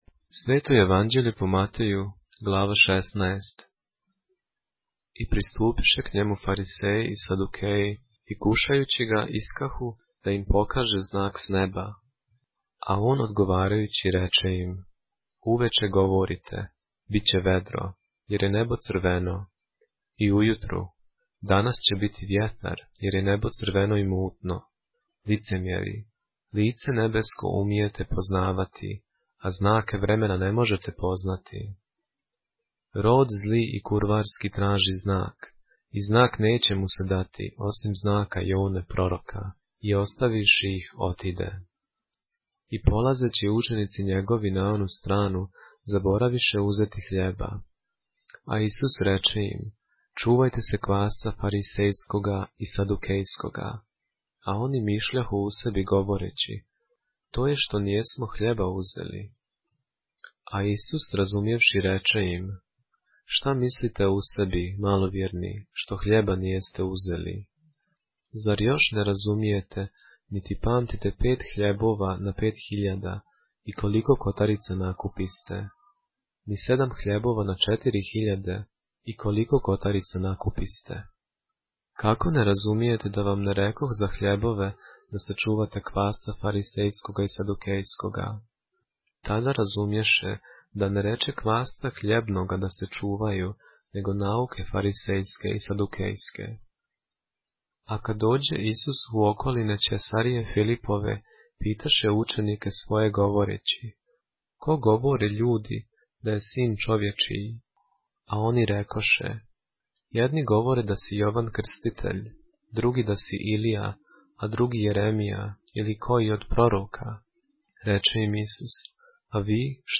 поглавље српске Библије - са аудио нарације - Matthew, chapter 16 of the Holy Bible in the Serbian language